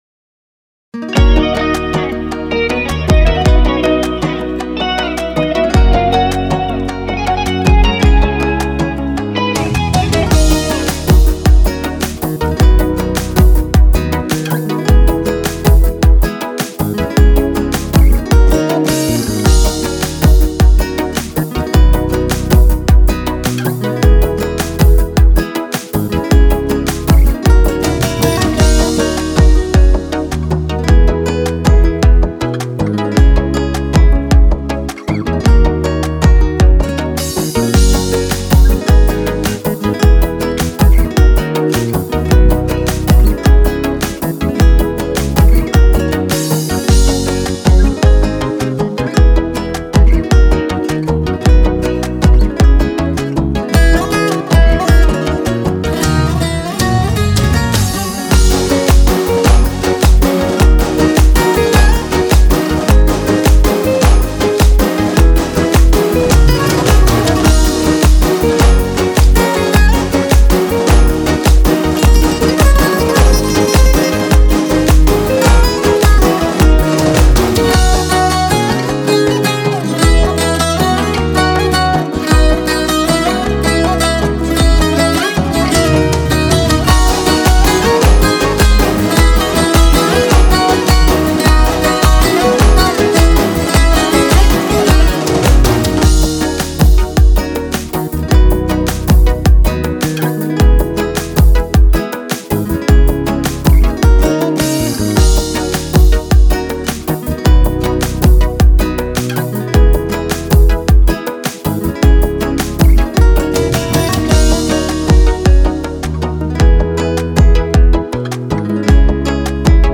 فایل بی کلام